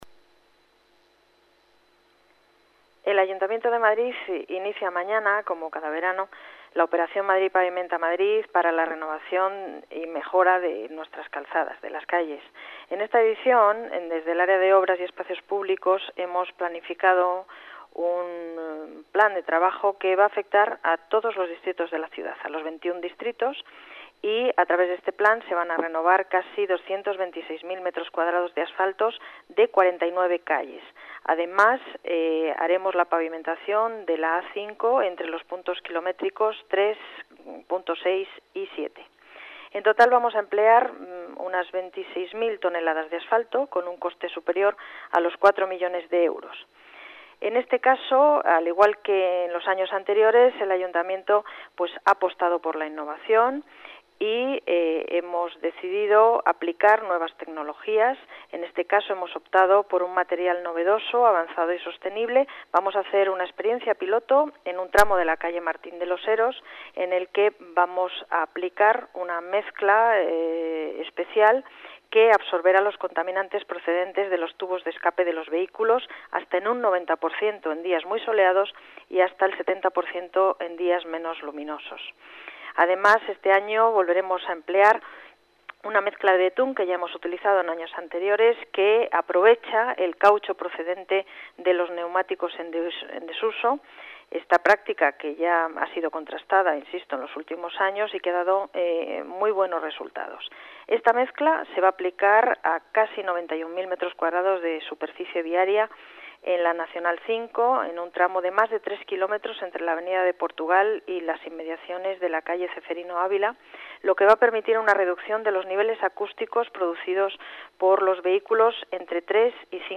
Nueva ventana:Declaraciones de la delegada de Obras y Vías Públicas, Paz González: operación Madrid pavimenta Madrid